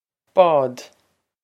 Pronunciation for how to say
bawd
This is an approximate phonetic pronunciation of the phrase.